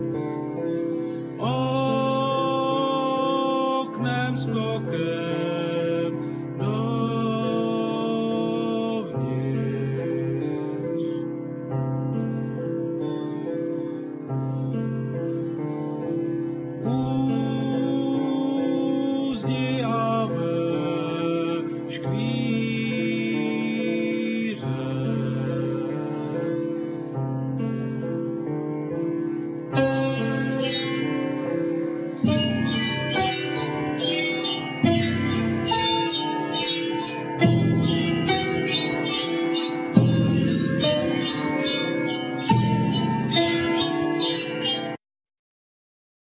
Piano,Vocal,Drum,Vibraphone,Glockenspiel
Violin,Piano,Vocal,Vibraphone,Glockenspiel